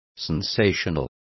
Also find out how ruidosas is pronounced correctly.